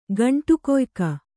♪ gaṇṭu koyka